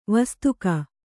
♪ vastuka